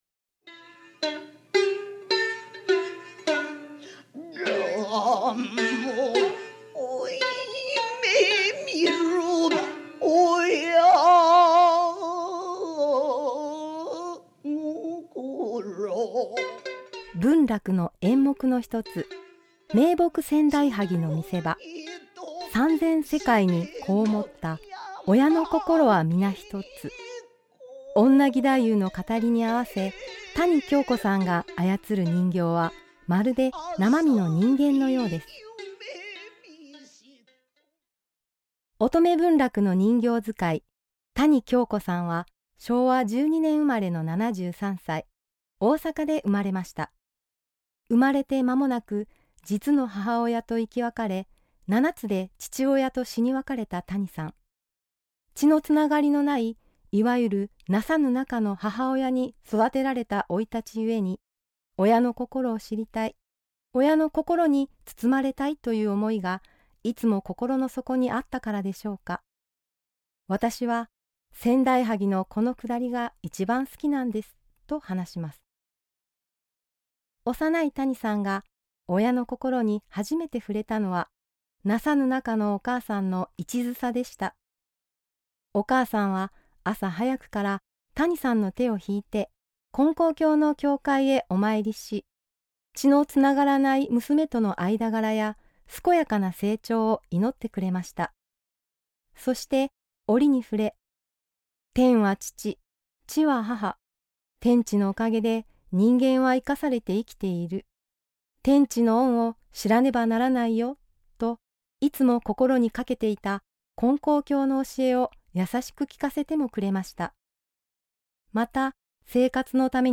●信者さんのおはなし